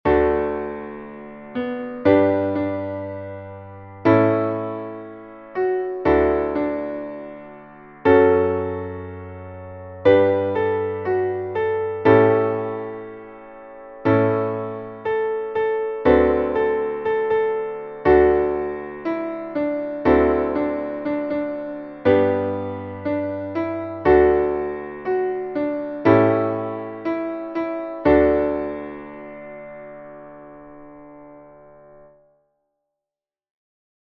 Traditional Spiritual